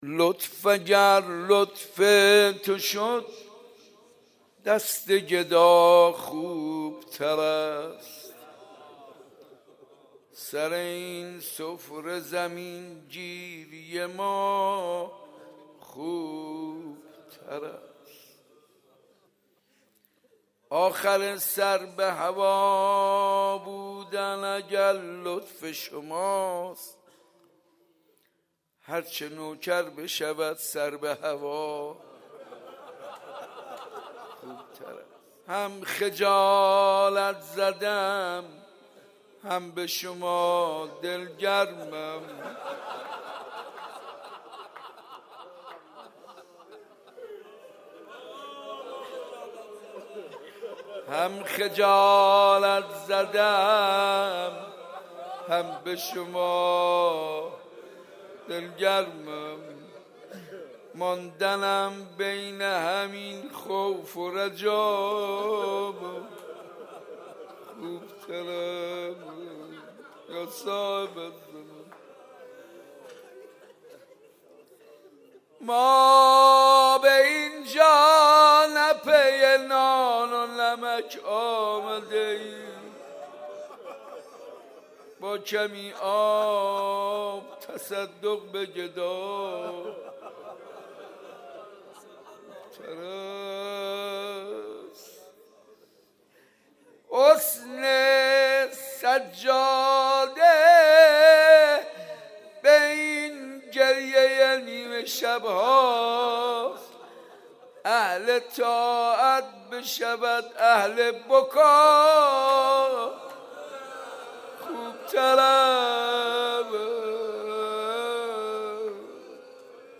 مراسم زیارت عاشورا و مناجات و قرائت زیارت عاشورا و روضه حضرت ام الکلثوم (سلام الله علیها) توسط حاج منصور ارضی برگزار گردید